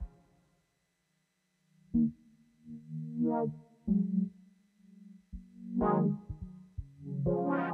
Ребзя, у кого XM, он шумит сильно сам по себе или это у меня брак? Moog, Prophet совсем не шумит по сравнению с ним.
Вложения noise.mp3 noise.mp3 244,1 KB · Просмотры: 872